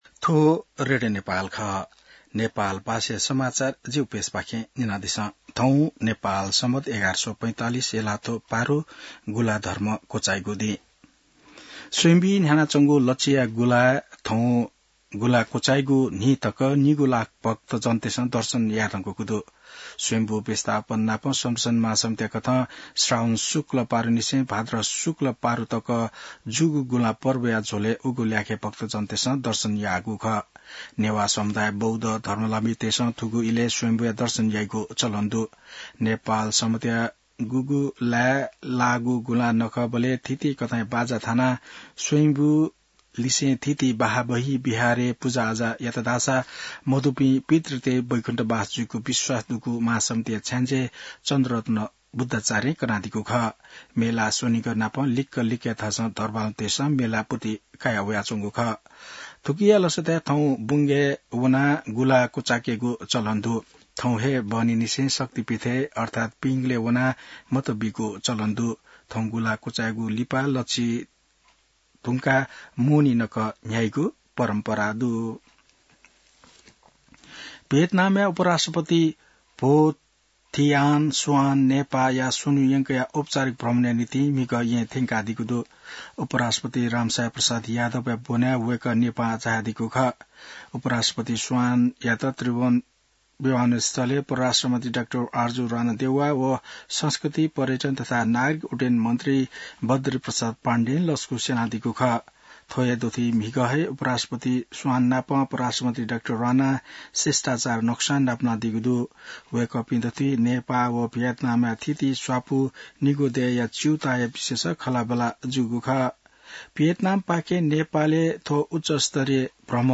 नेपाल भाषामा समाचार : ८ भदौ , २०८२